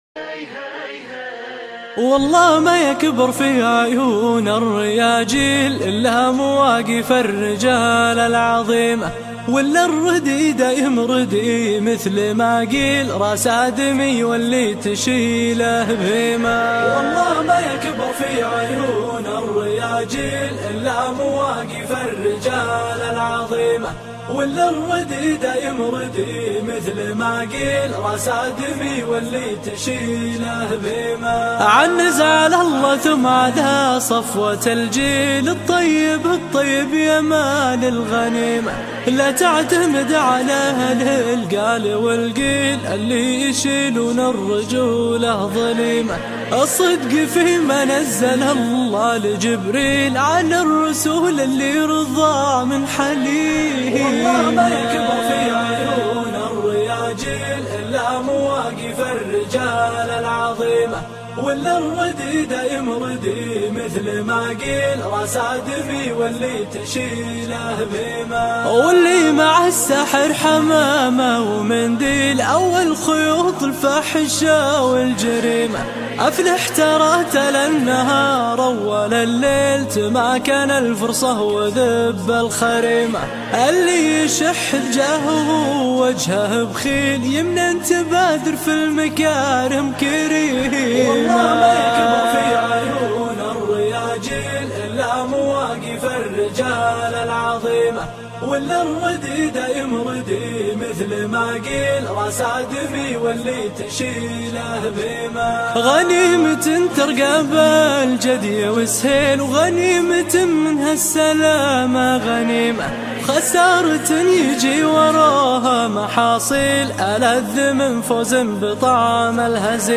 شيلة